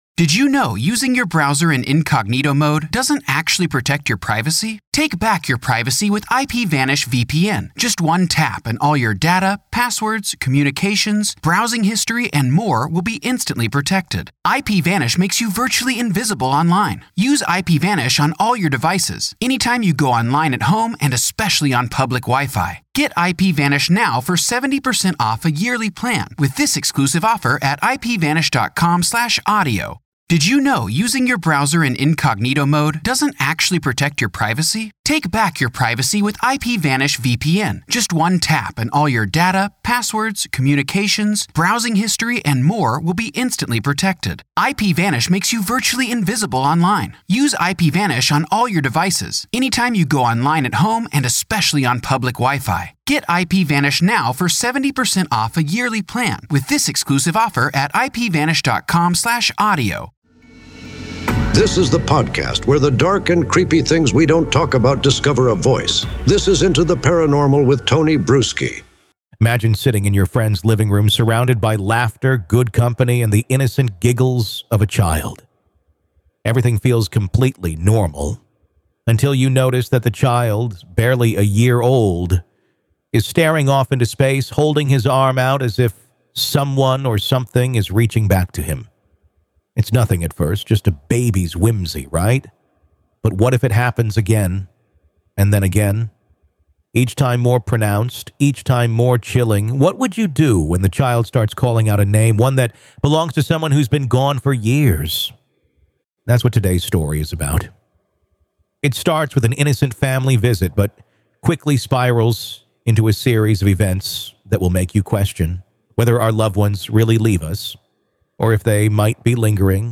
Have you ever felt a connection to someone you’ve never met? In this haunting episode, a storyteller recalls growing up feeling inexplicably close to their late grandfather—someone they never had the chance to meet in life.